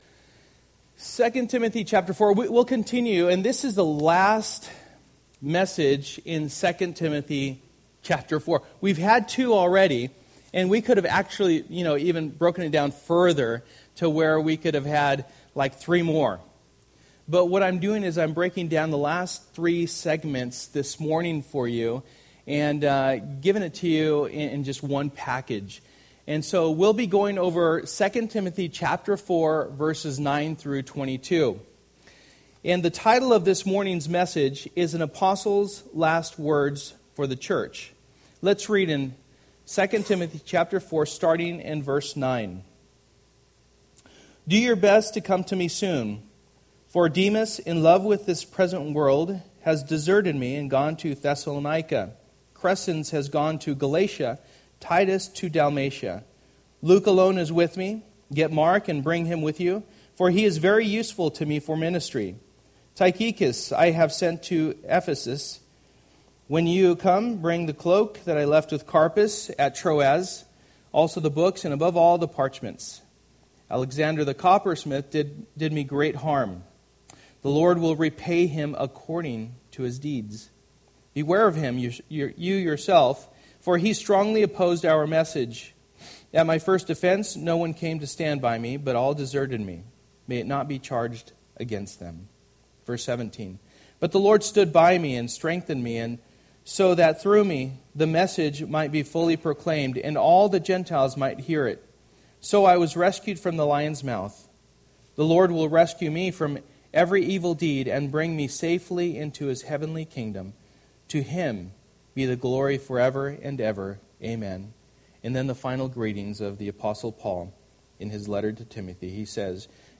Model & Guard Passage: 2 Timothy 4:9-22 Service: Sunday Morning %todo_render% « Power of the Holy Spirit